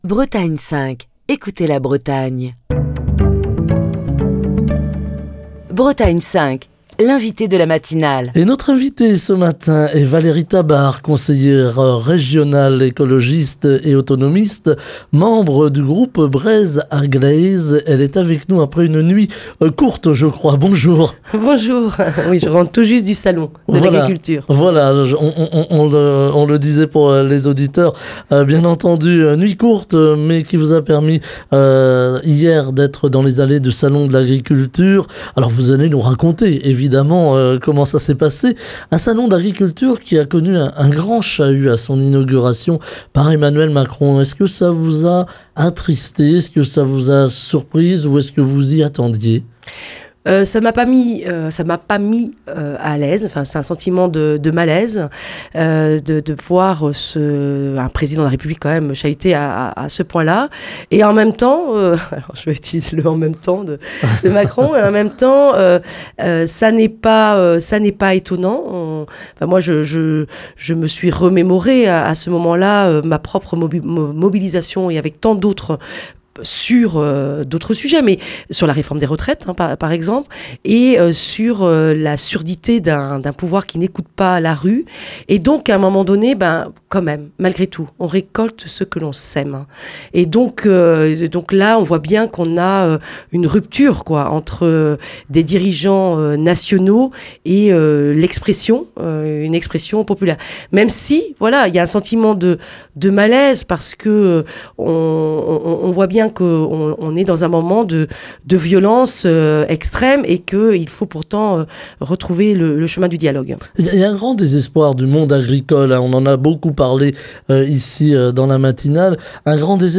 Valérie Tabart, conseillère régionale écologiste et autonomiste, membre du groupe Breizh a-gleiz est l'invitée de Bretagne 5 Matin, pour évoquer le Salon de l'agriculture où elle était hier, la crise agricole et le dialogue nécessaire pour un changement de modèle et une évolution des pratiques, et la montée des idées du Rassemblement national dans les rangs des agriculteurs à quelques mois des élections européennes. Enfin, Valérie Tabart revient sur le vote hier soir, par une large majorité des sénateurs, pour l'inscription de l'IVG dans la Constitution.